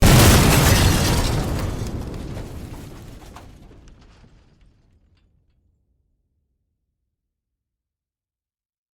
Nuke Explode with Glass
SFX
Nuke Explode with Glass is a free sfx sound effect available for download in MP3 format.
yt_ta98jEhNSQw_nuke_explode_with_glass.mp3